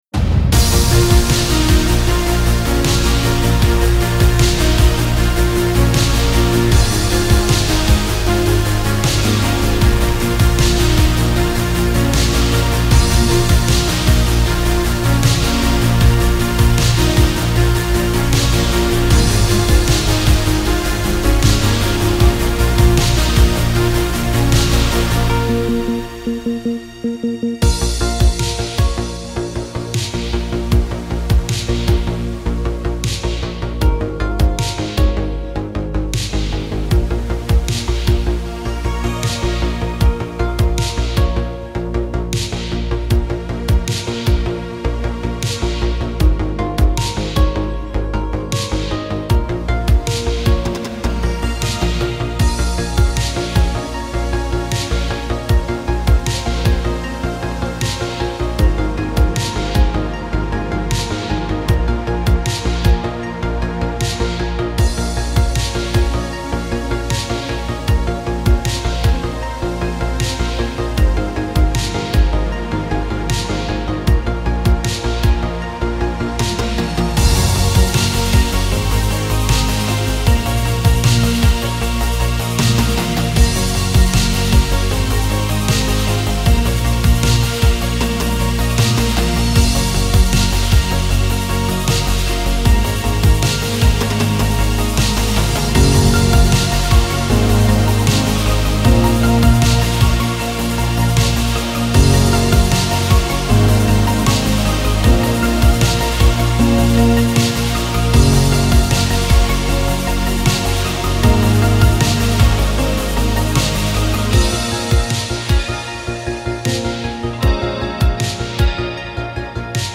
Genre: Synthspace, Electronic.